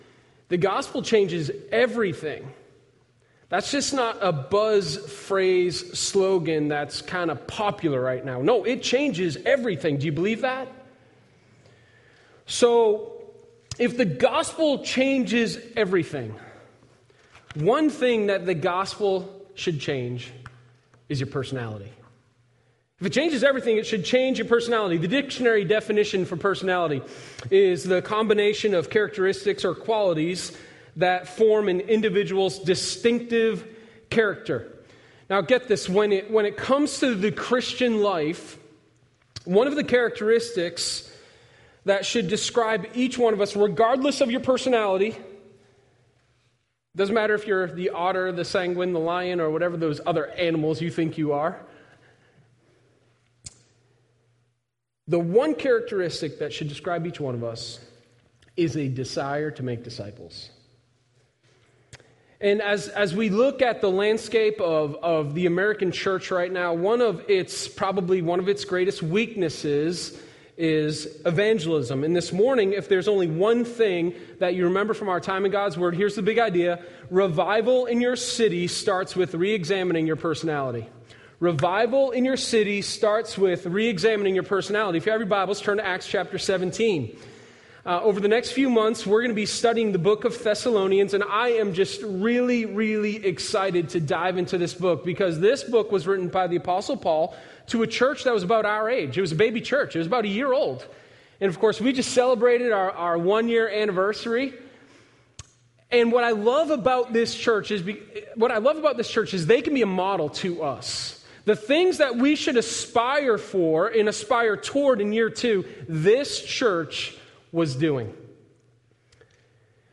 Sermon1016_1ChangeYourPersonality.mp3